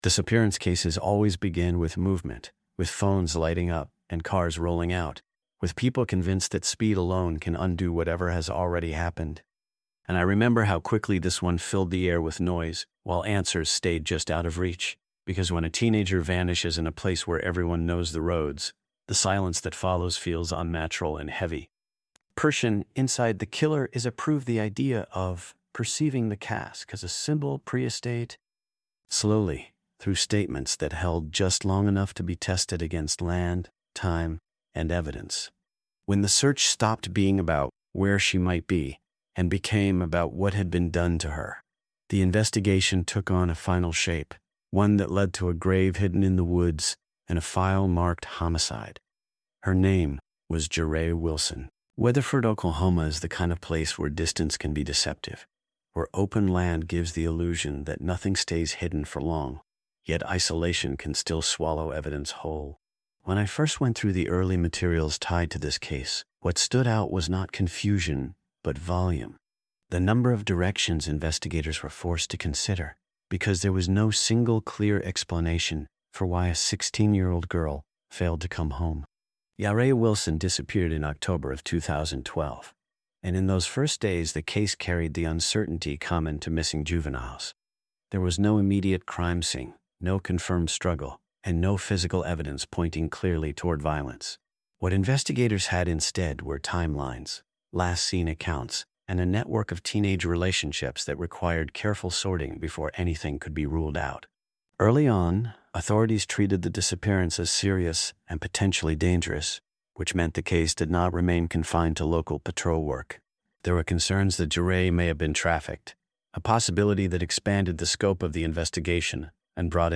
This true-crime audiobook follows the investigation exactly as it unfolded, grounded strictly in verified records and courtroom facts.